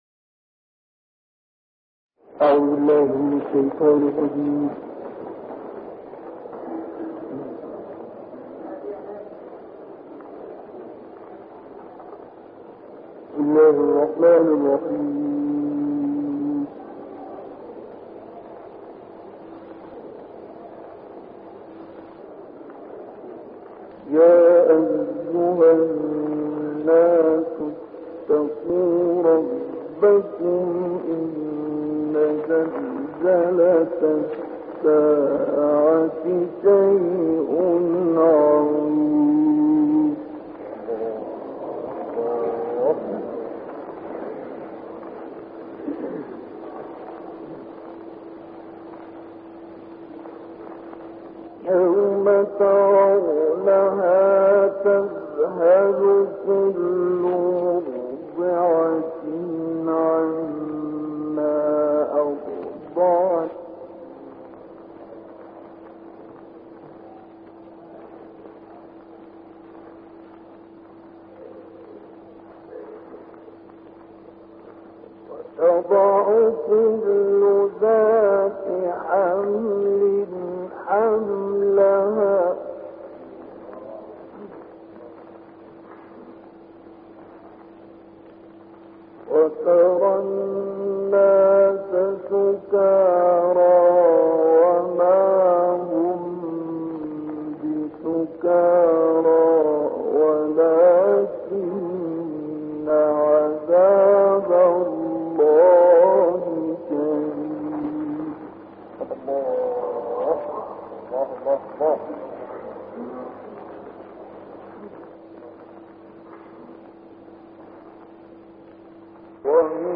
تلاوتی بسیار زیبا از سوره حج که توسط بزرگترین قاری تمام دورانها استاد مرحوم مصطفی اسماعیل در سال 1945 میلادی تلاوت گردید.